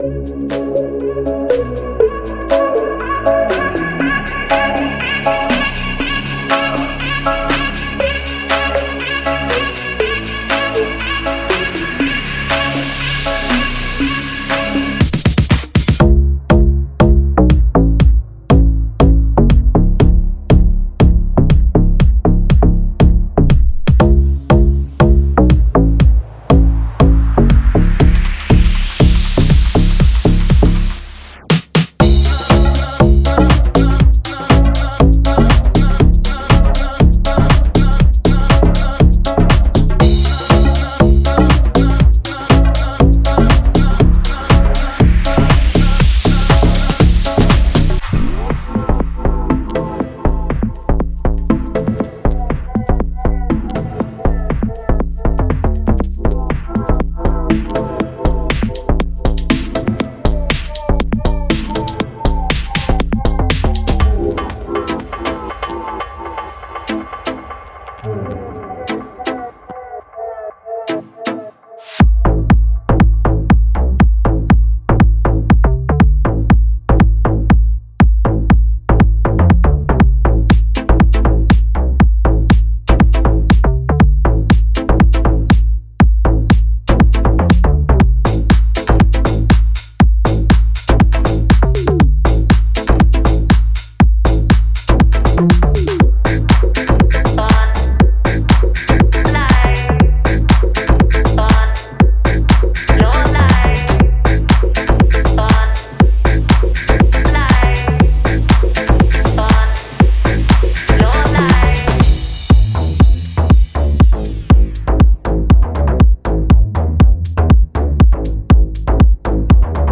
Here you’ll find Deep & Wobble Basses, wide range of pumping House Leads, stabby Plucks & top-notch Synths, and of course cosmic FXs.
Ultimate House Presets (Serum) fits perfect for all House producers making Classic House, Deep House, Progressive House, Future House, Bass House and more!